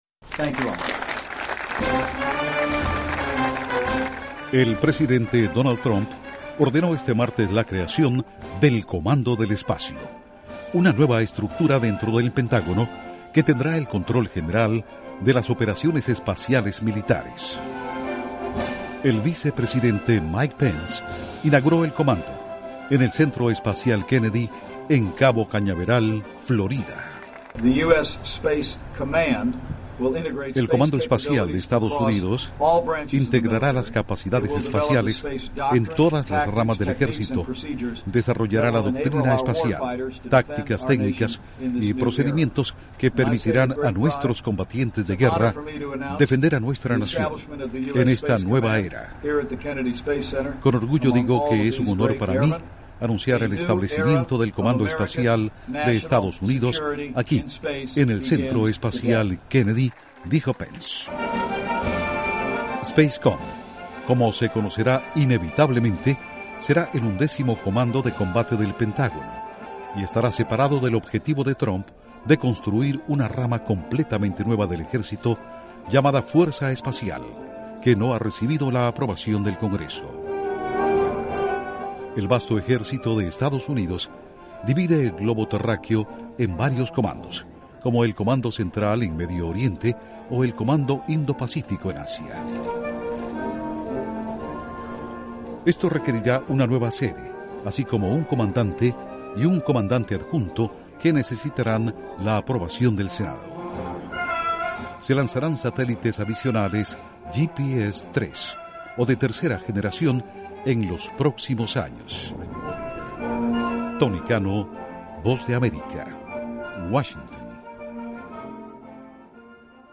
Duración: 2:00 Audios de Mike Pence/Vicepresidente de Estados Unidos Audios de la inauguración